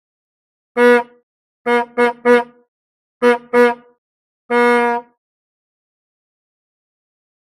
Звуки такси
Гудок старинного такси